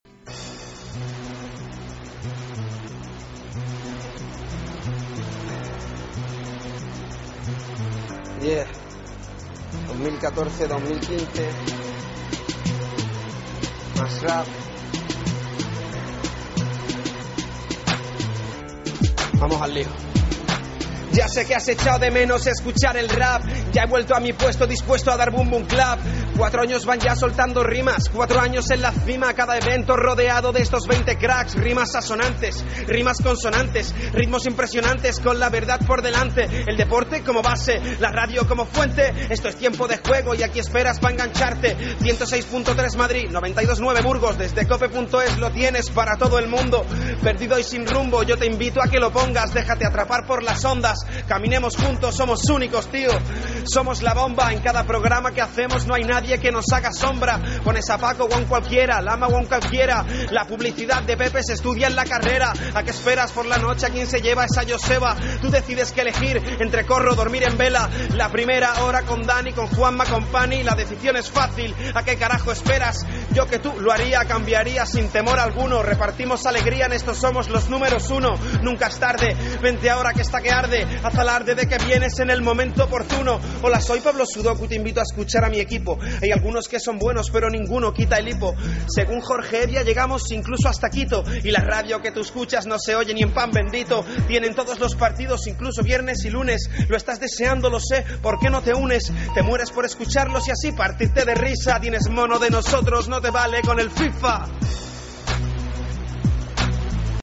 a ritmo de rap